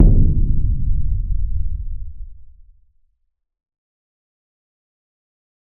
Big Drum Hit 36.wav